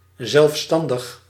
Ääntäminen
IPA: /zɛlf.stɑn.dɪɣ/